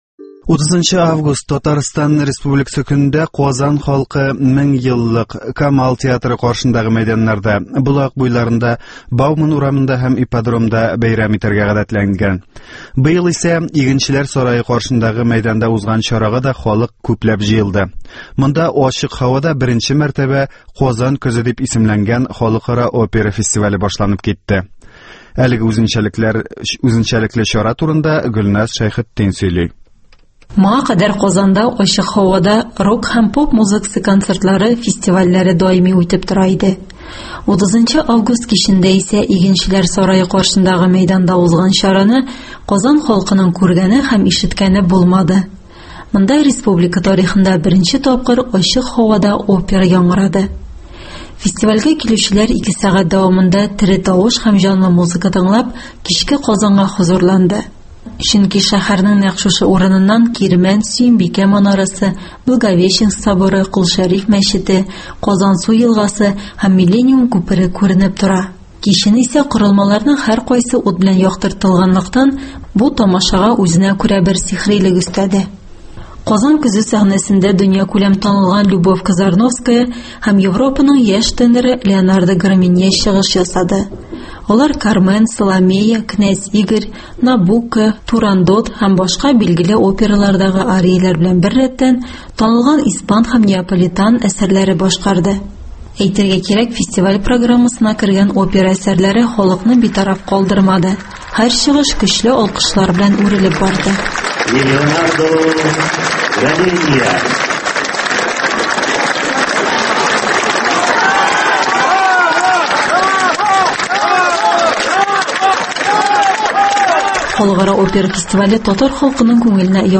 Любовь Казарновская “Туган тел”не башкарды